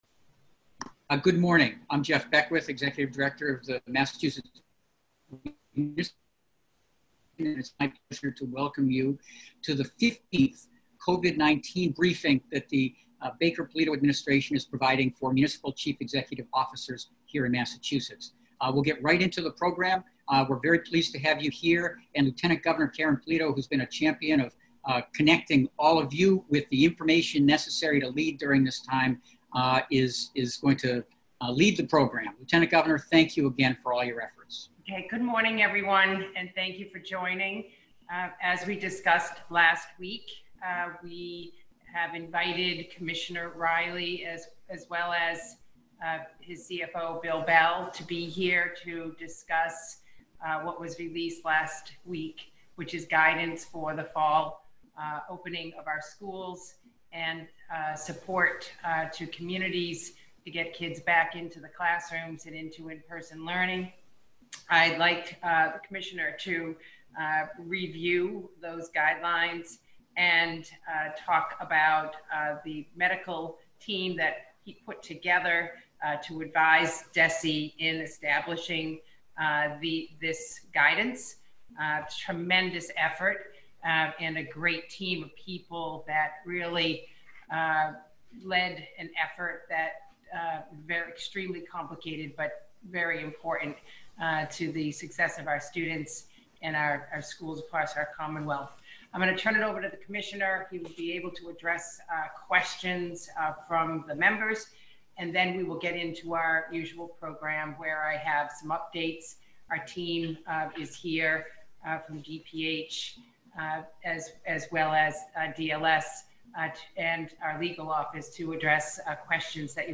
Lt. Gov. Karyn Polito speaks with local officials virtually on June 23.
MMA_Admin_COVID19_BriefingAudio_July1.mp3